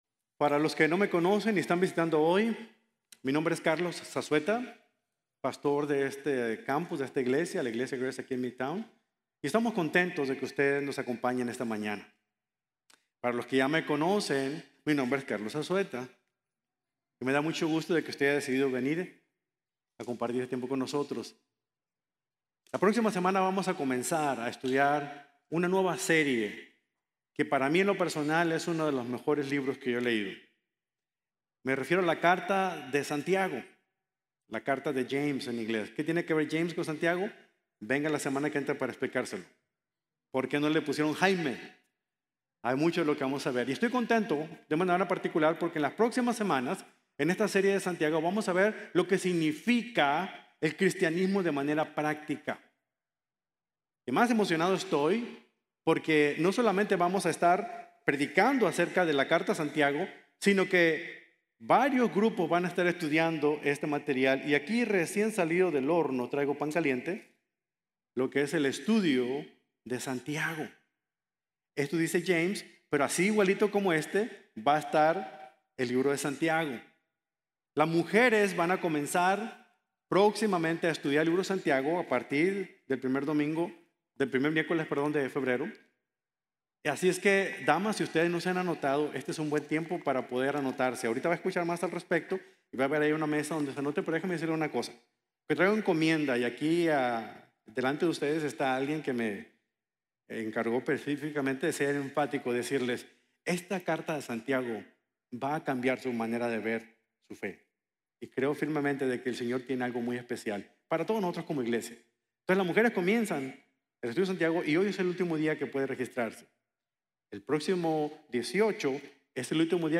Mentalidad “Plus-One” | Sermon | Grace Bible Church